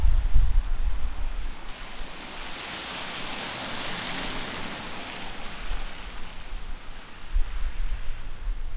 It was interesting to hear the 3D sound that a car is passing by from right to left, which made the experience immersive.
Street recording.
3D street sound.